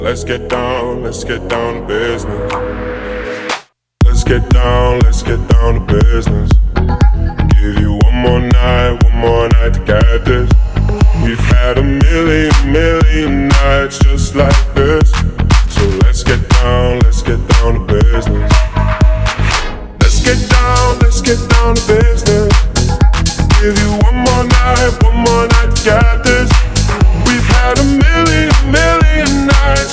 Genre: Dance